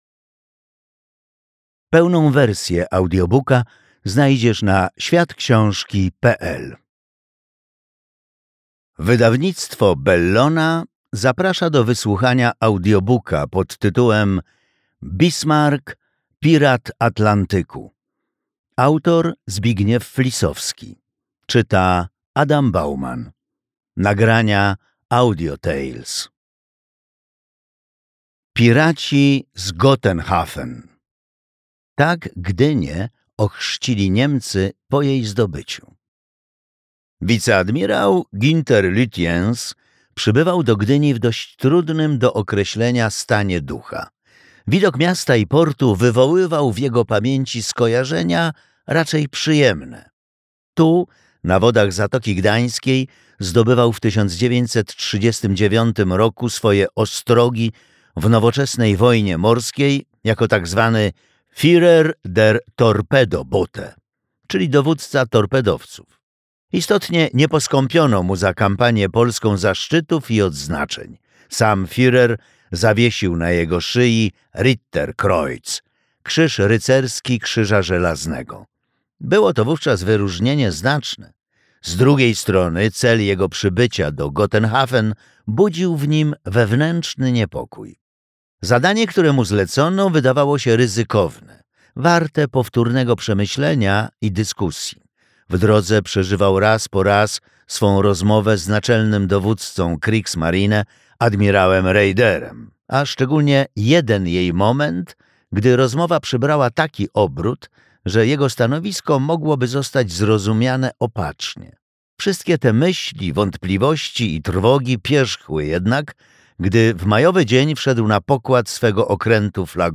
Bismarck pirat Atlantyku - Zbigniew Flisowski - audiobook